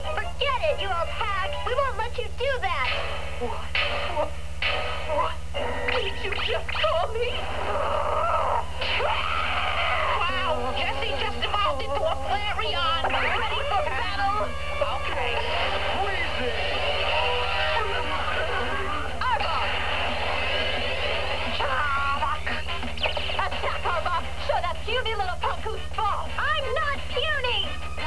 The TRUE Evil in Jessie- I believe that this sound clip is AWESOME! It brings out the TRUE evil in Jessie, and how enraged she gets when Misty says something stupid.